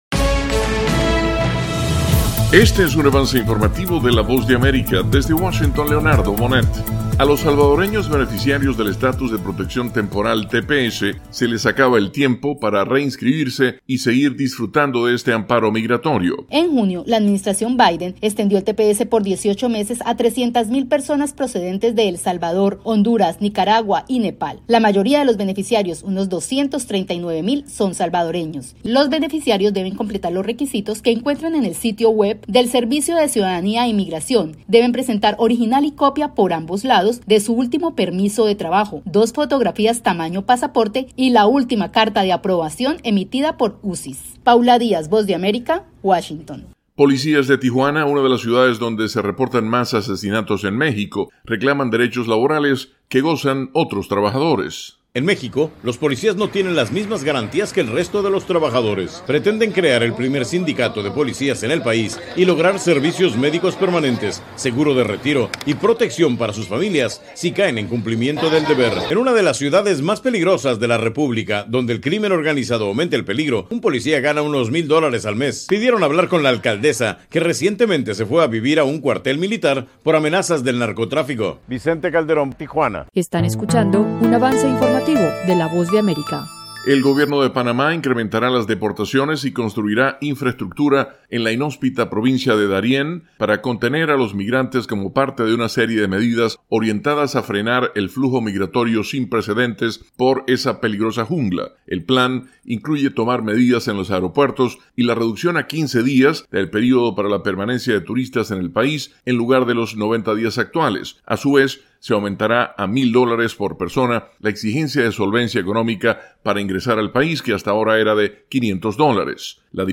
Avance Informativo 3:00 PM